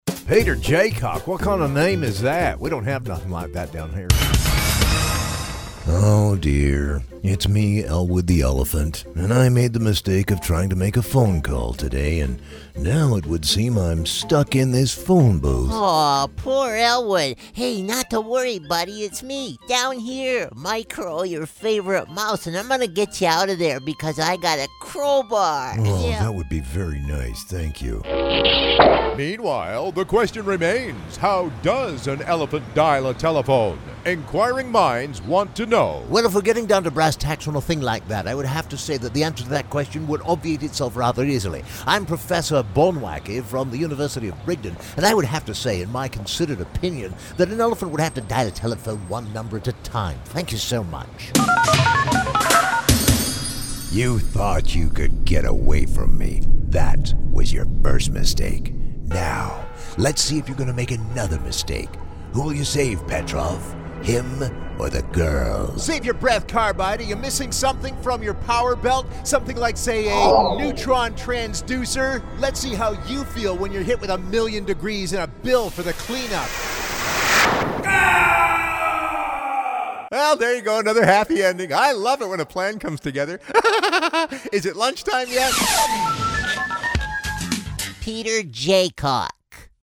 Smooth, deep, fun, animation, expressive, cartoon, casual
englisch (us)
Sprechprobe: Sonstiges (Muttersprache):